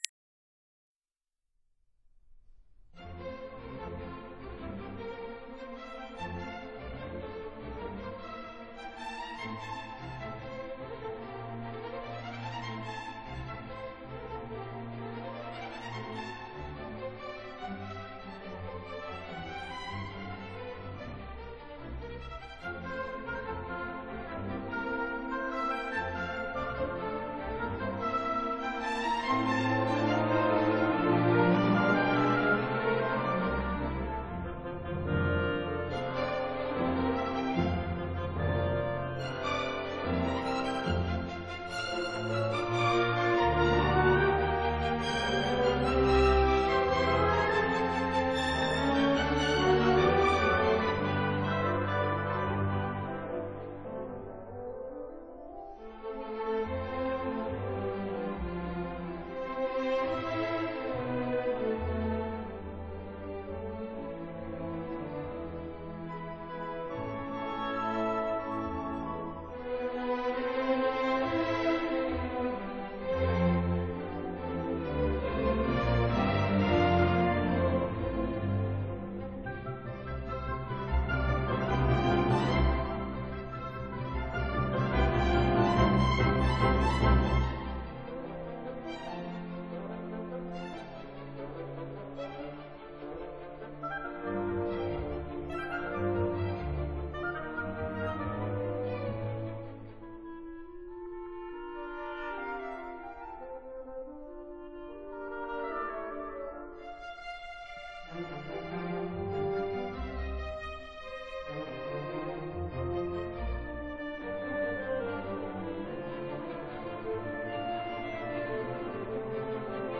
Allegro Molto Vivo
Tempo Di Minuetto. Allegretto Moderato
Allegro Vivo